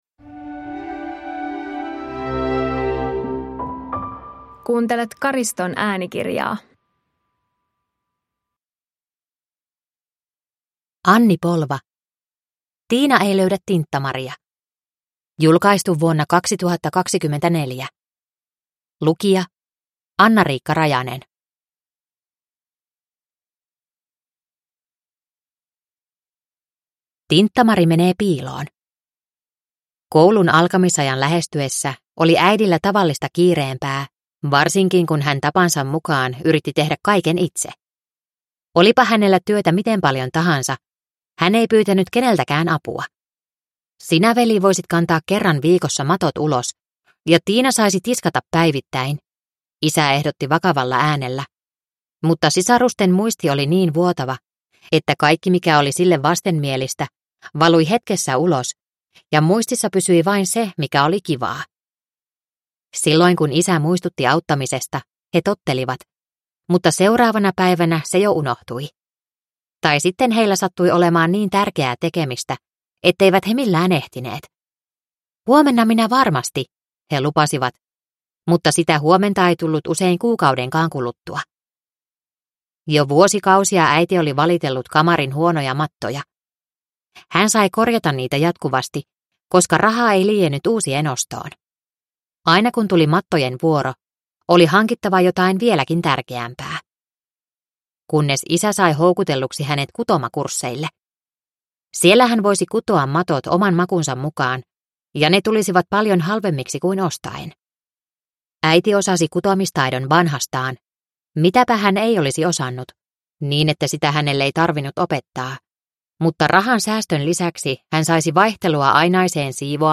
Tiina ei löydä Tinttamaria (ljudbok) av Anni Polva